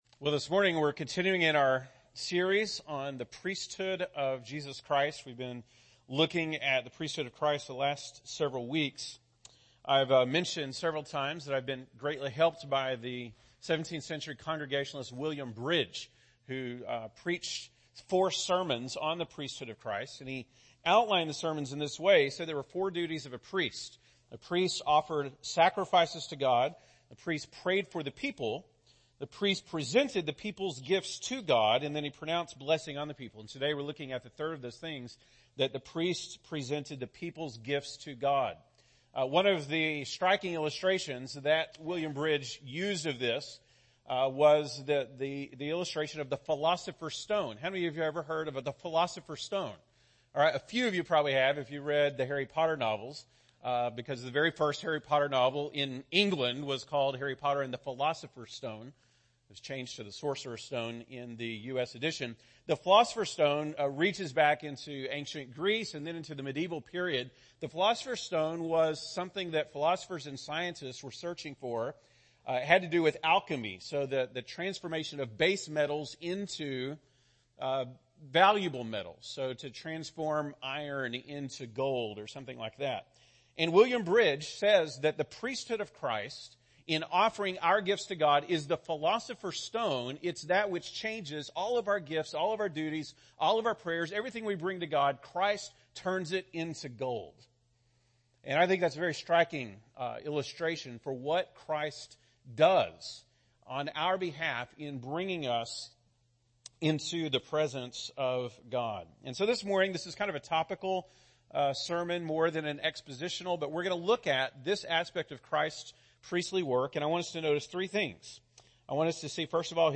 September 2, 2018 (Sunday Morning)